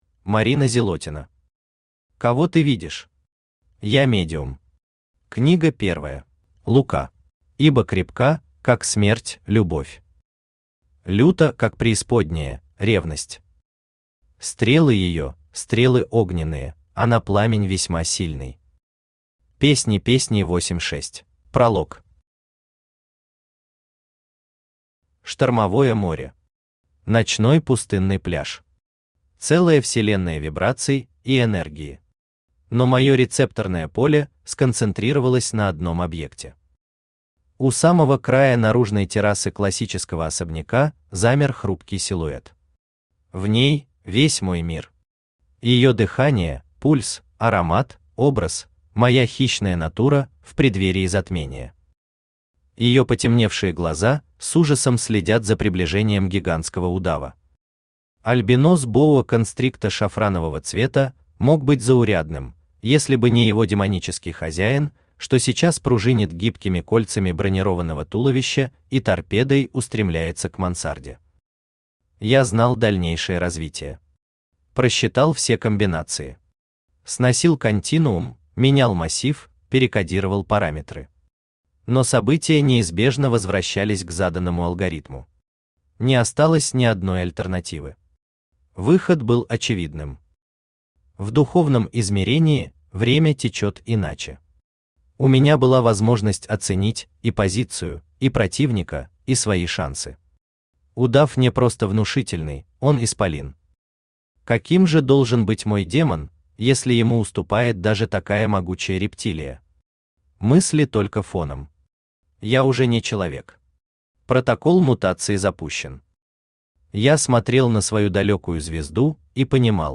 Аудиокнига Кого ты видишь? Я медиум. Книга первая | Библиотека аудиокниг
Книга первая Автор Марина Зилотина Читает аудиокнигу Авточтец ЛитРес.